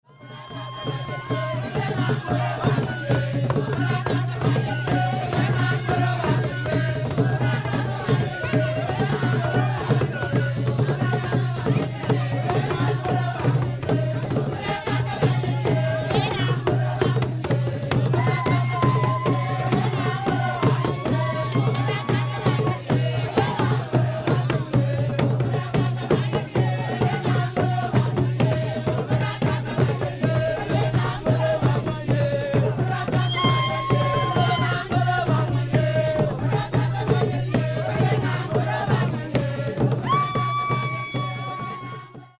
The dunung is a double-headed closed cylindrical drum that is struck with one stick and damped with the finger-tips. It produces a high tone with a long echo.